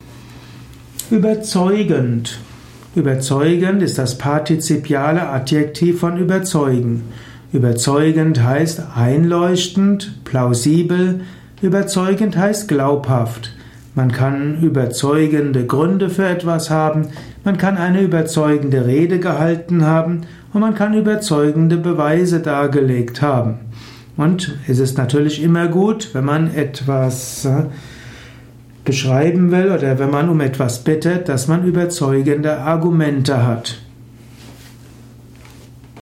Erfahre einiges über Überzeugend in diesem Audiovortrag.
Dieser Audio Podcast über \" Überzeugend \" ist die Tonspur eines Videos , zu finden im Youtube Kanal Persönlichkeit, Ethik und Umgang mit Schattenseiten .